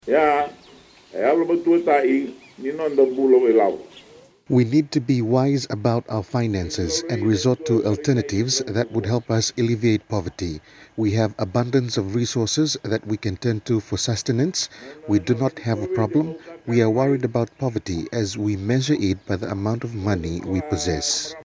Prime Minister Sitiveni Rabuka during his tour to Navatu in Savusavu
He stressed the issue during his address to the people of Navatu in Savusavu earlier this week and applauded their men and women who have opted to forego finer things such as “kalavata” during celebrations.